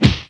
PUNCH  3.WAV